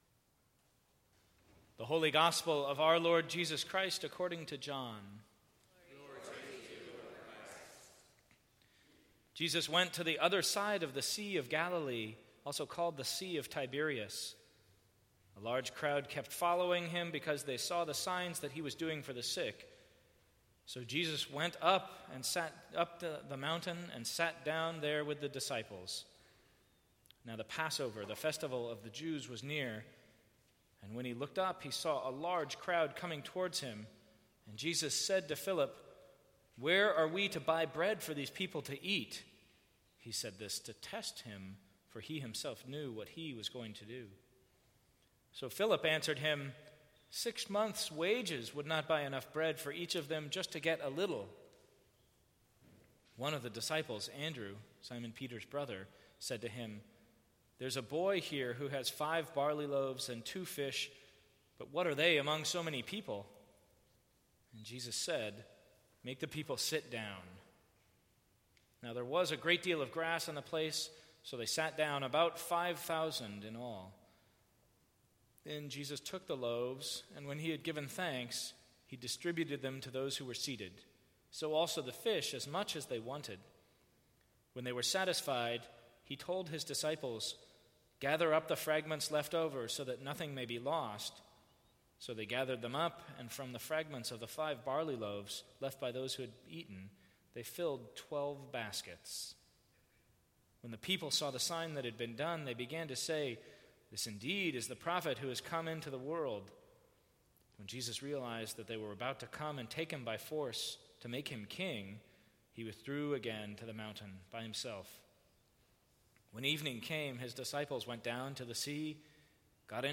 Sermons from St. Cross Episcopal Church The Loaves and the Fishes: what kind of miracle was this?